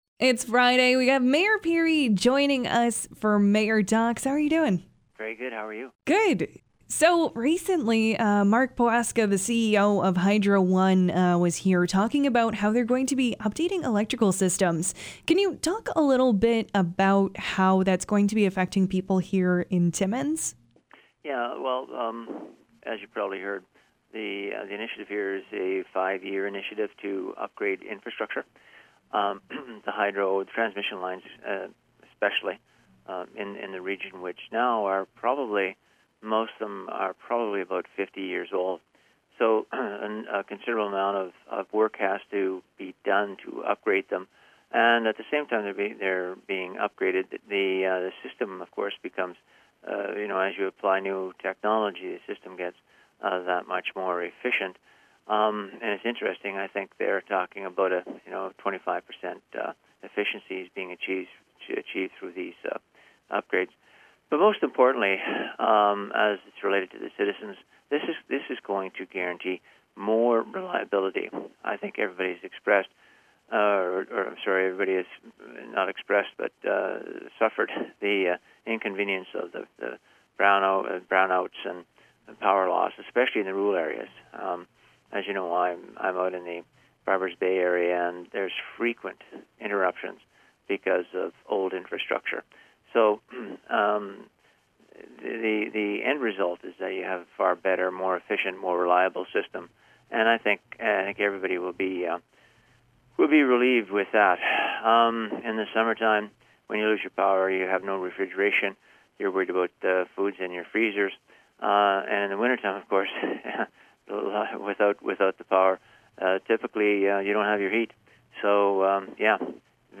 Mayor Pirie called into Moose Mornings to talk about Mark Poweksa, the CEO of Hydro One visiting Timmins and the plan for upgrading that we’re going to be seeing over the next few years.